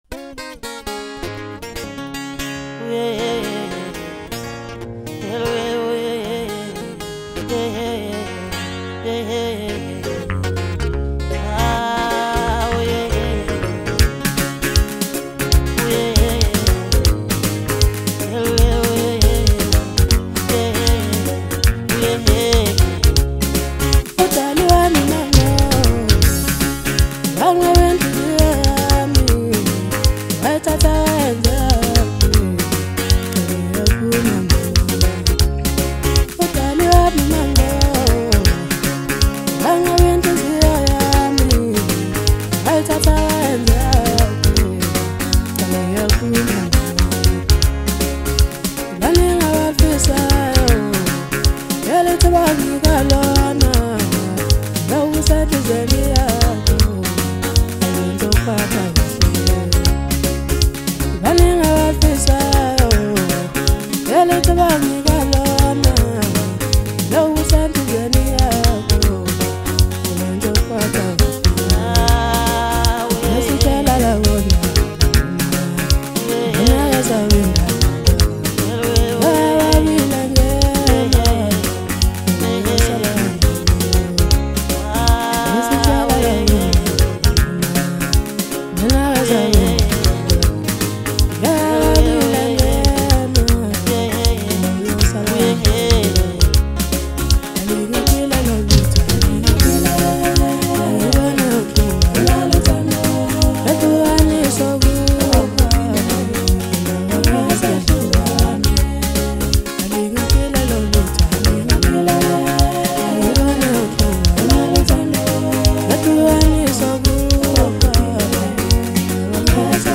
Home » Maskandi
captivating and harmonious tune